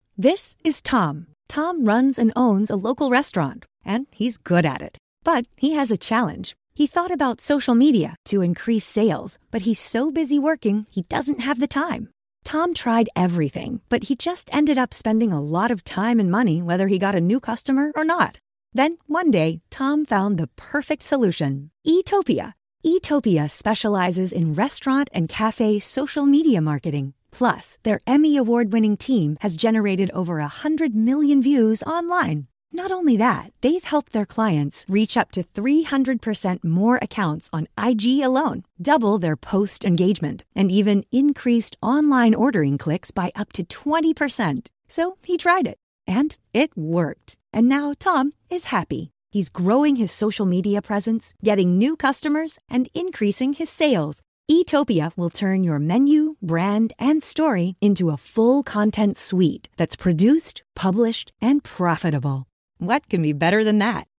Seasoned voiceover actor with a broad range of skills
Explainer Demo